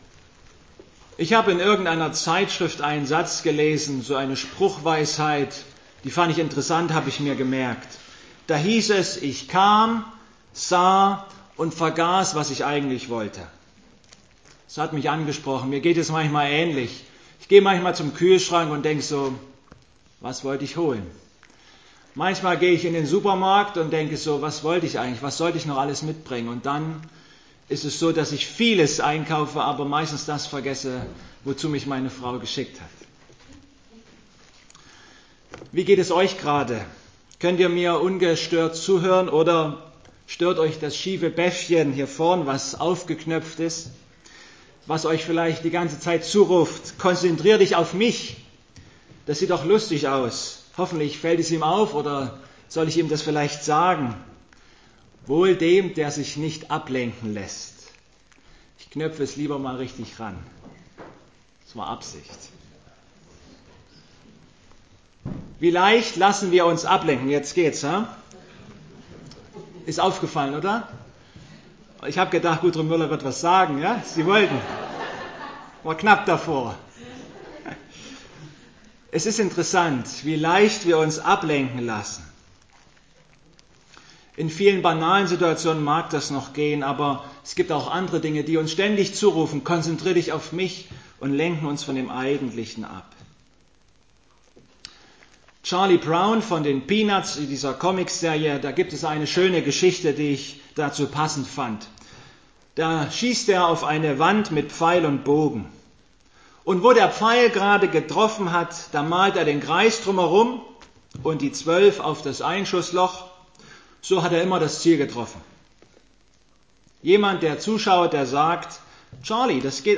Philipper 3,7-14 Gottesdienstart: Abendmahlsgottesdienst Die vergangenen Tage standen ganz im Zeichen der Leichtathletik-WM in London.